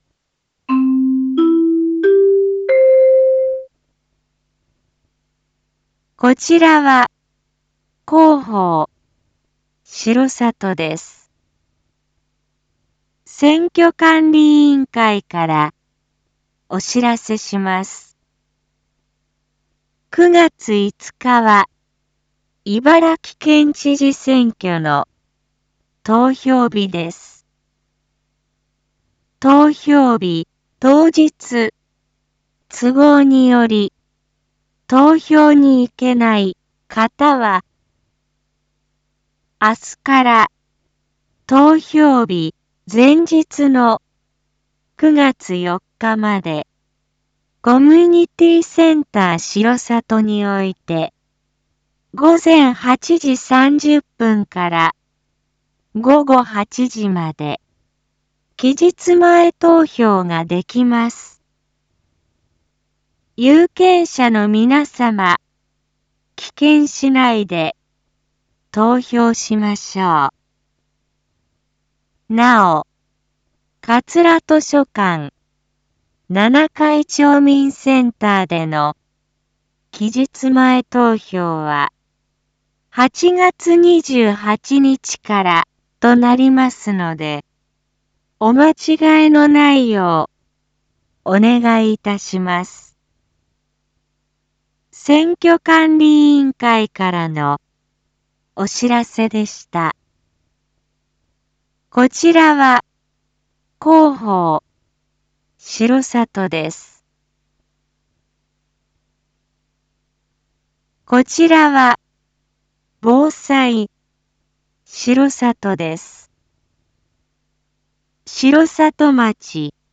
一般放送情報
Back Home 一般放送情報 音声放送 再生 一般放送情報 登録日時：2021-08-19 19:03:29 タイトル：茨城県知事選挙期日前投票① インフォメーション：こちらは広報しろさとです。